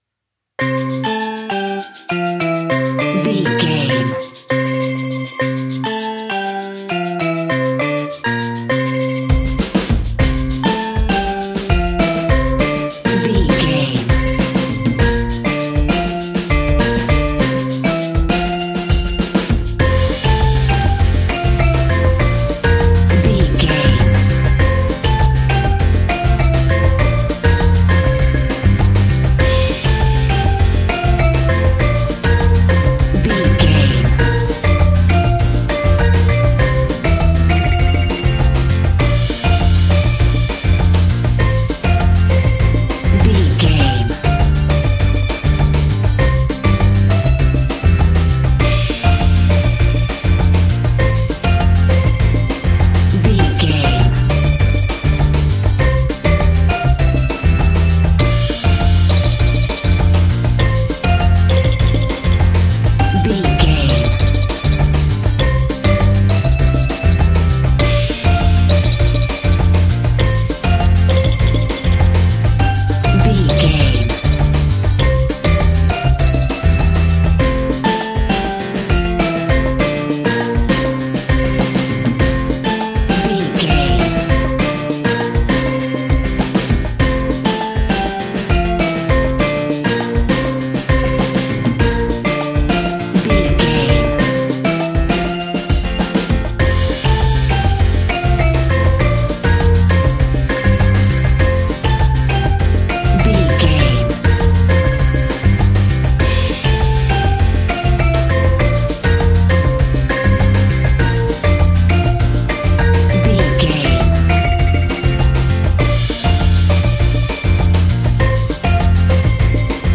Uplifting
Ionian/Major
steelpan
drums
percussion
bass
brass
guitar